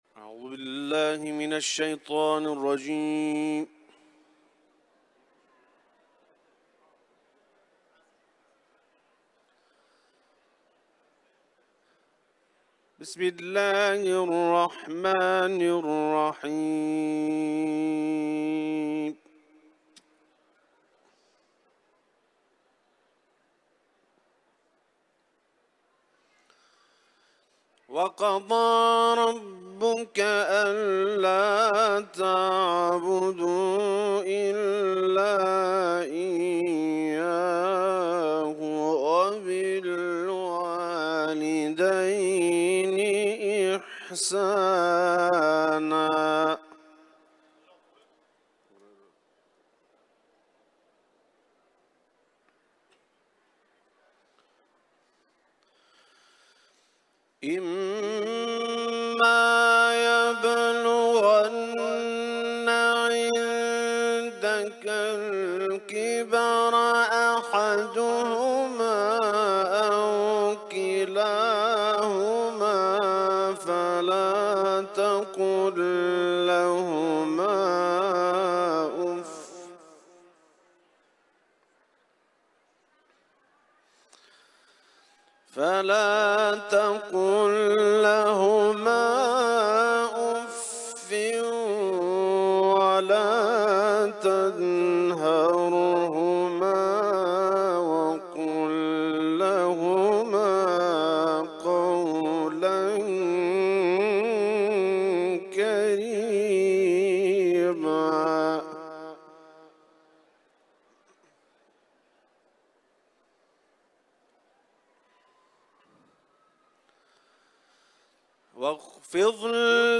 تلاوت قرآن